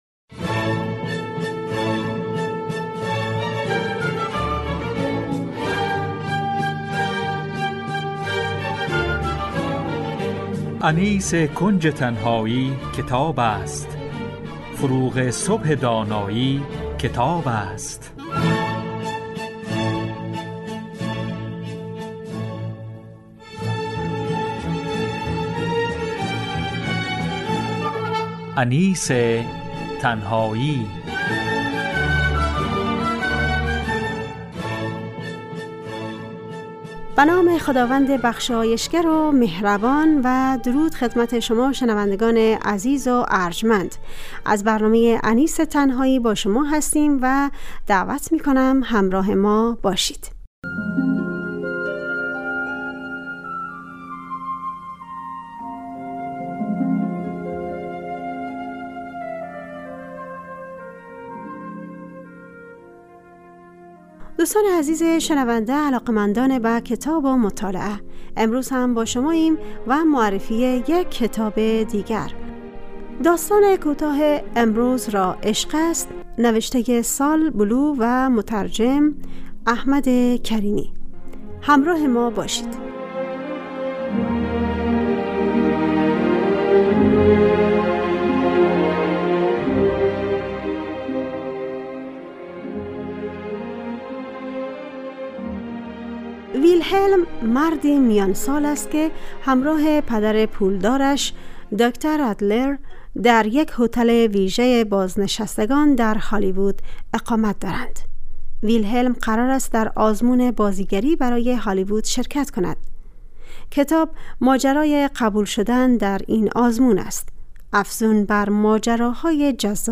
معرفی کتاب
همراه با گزیده ای از سطرهایی از کتاب